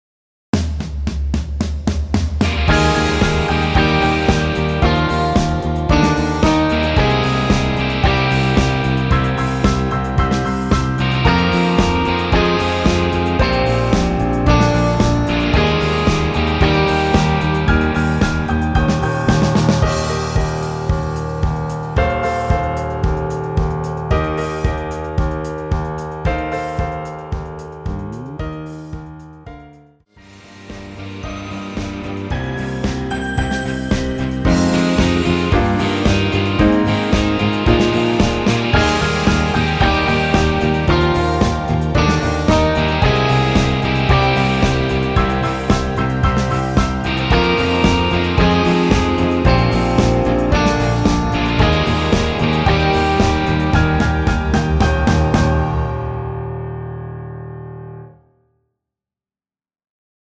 엔딩부분이 페이드 아웃이라 아~부분을 드럼 들어가는곳 까지만으로 편곡 하여 엔딩을 만들었습니다.
Eb
앞부분30초, 뒷부분30초씩 편집해서 올려 드리고 있습니다.